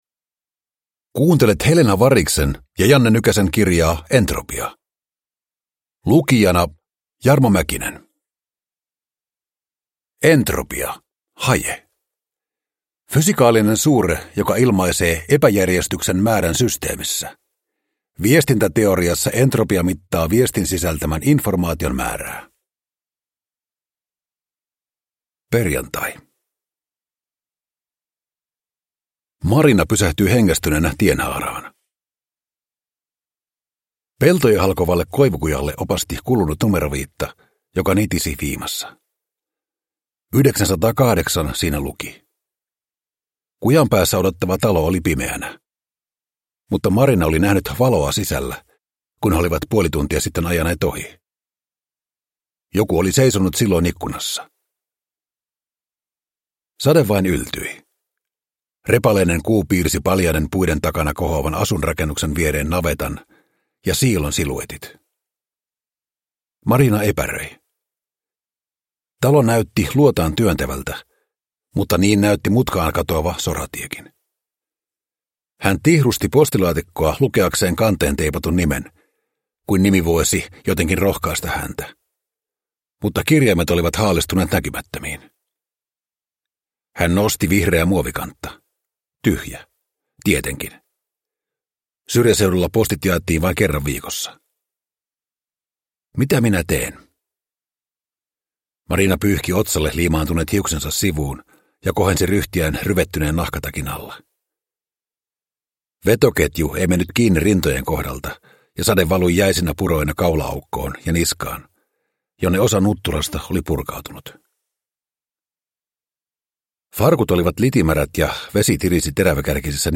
Entropia – Ljudbok